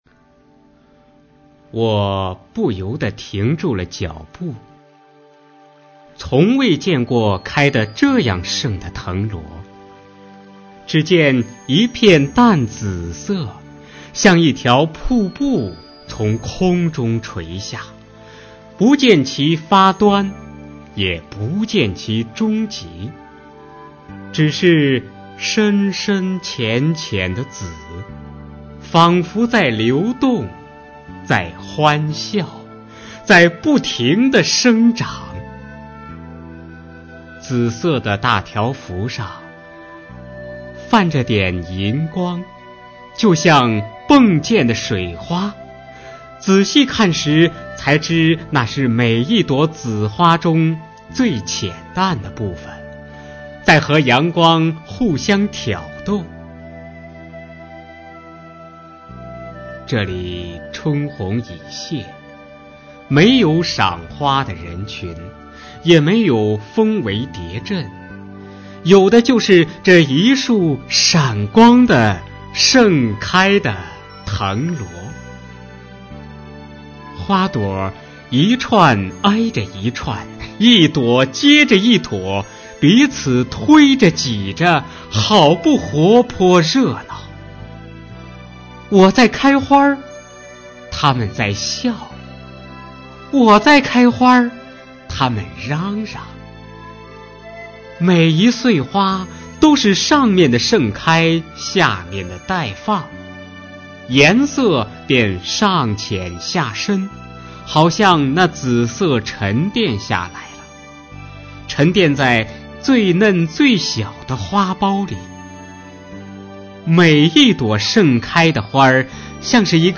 《紫藤萝瀑布》音频朗诵